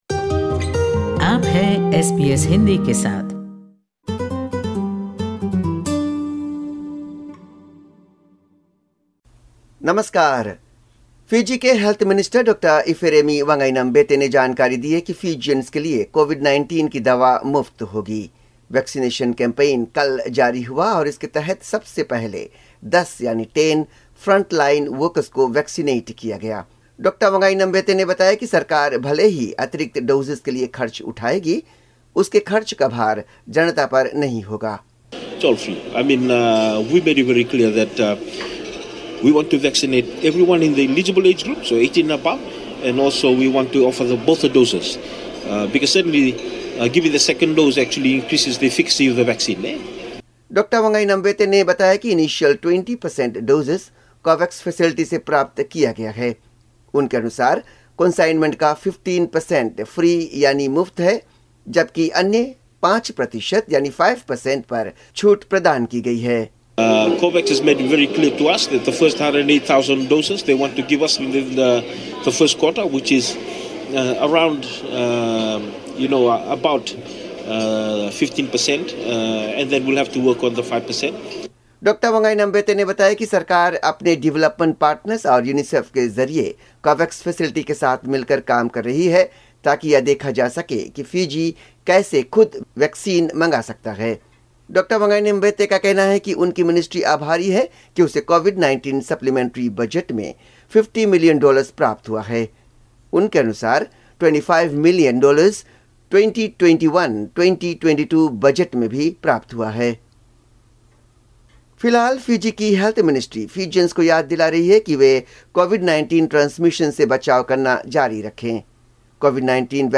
Fiji report in Hindi